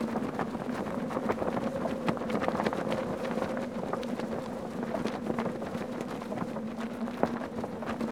PixelPerfectionCE/assets/minecraft/sounds/item/elytra/elytra_loop.ogg at mc116
elytra_loop.ogg